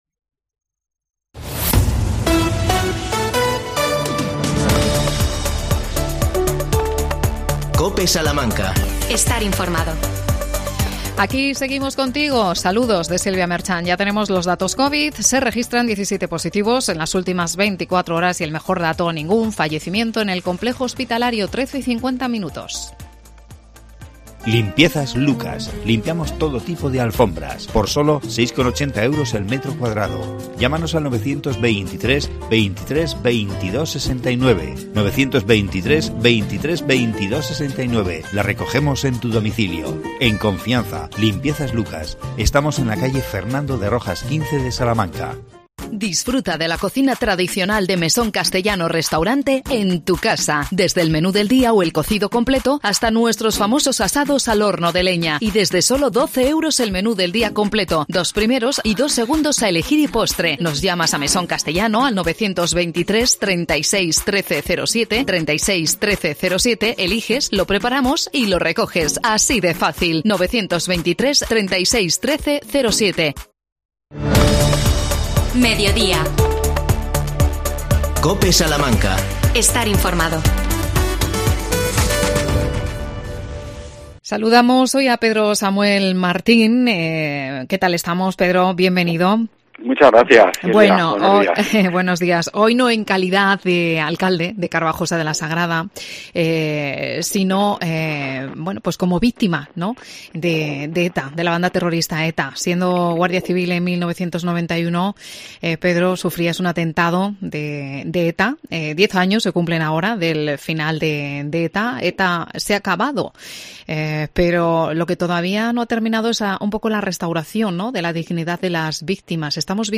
AUDIO: Entrevista a Pedro Samuel Martín, alcalde de Carbajosa y víctima de ETA. Microespacio Diputación de Salamanca.